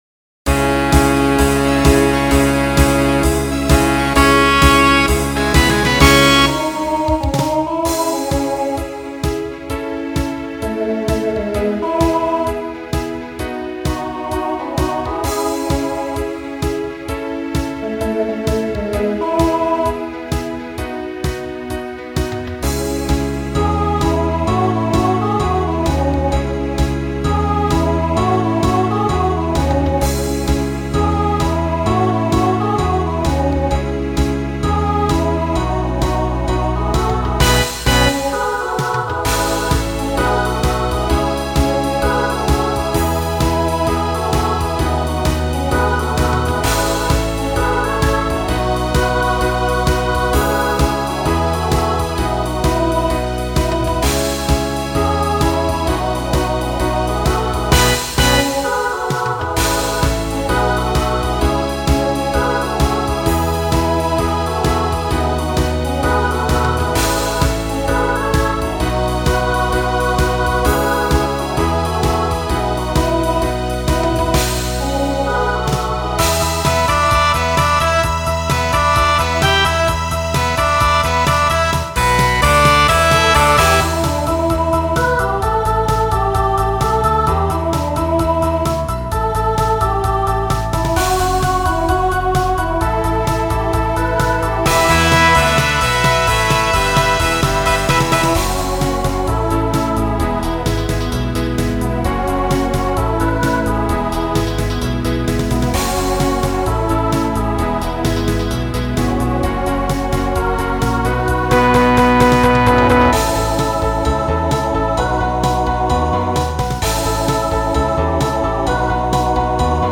Voicing SSA
Genre Pop/Dance